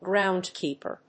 アクセント・音節gróund・kèeper